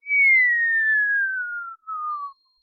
effect fail fall game whistle sound effect free sound royalty free Sound Effects